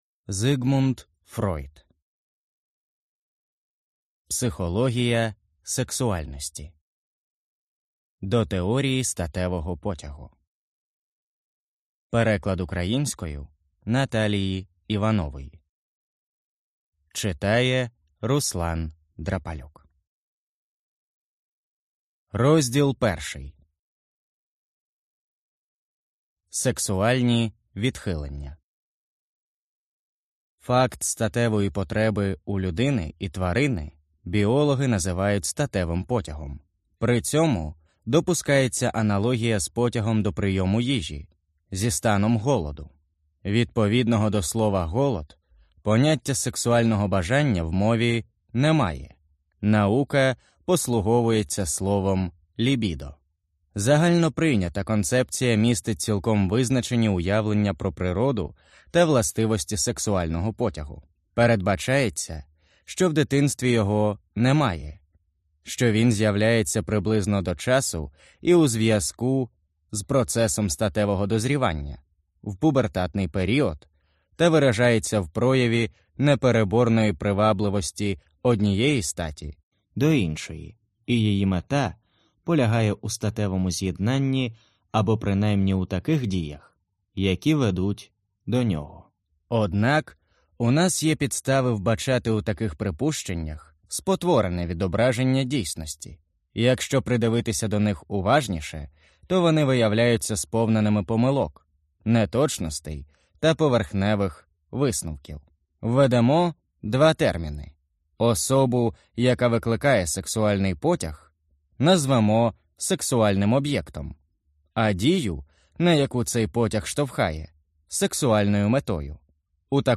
Аудиокнига Психологія сексуальності | Библиотека аудиокниг
Прослушать и бесплатно скачать фрагмент аудиокниги